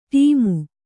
♪ ṭīmu